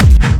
Kick 4.wav